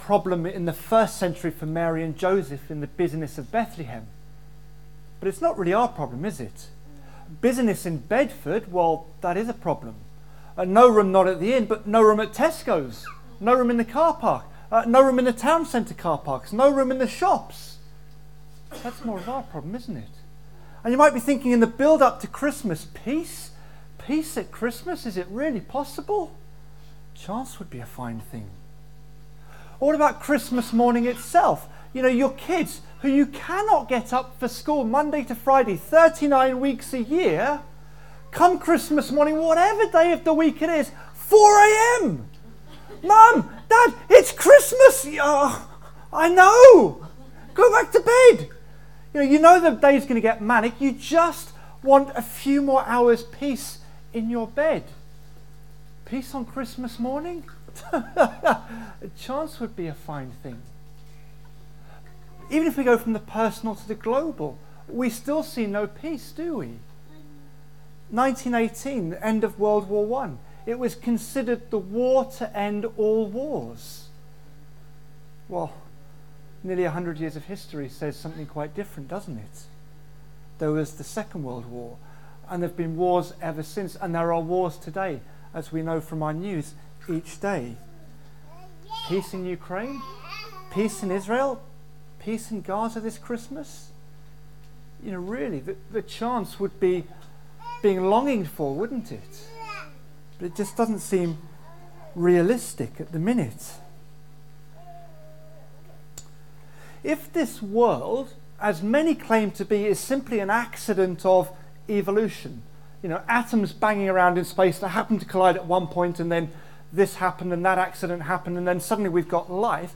Single Sermon | Hope Church Goldington
All-Age Carol Service 2023